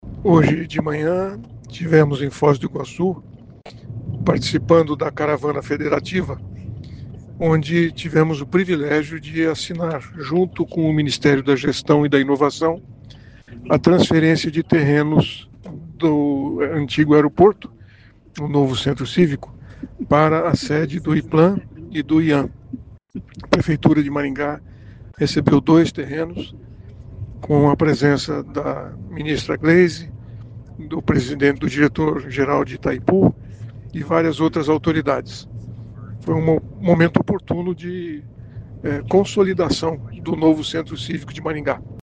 Ouça o que diz o prefeito: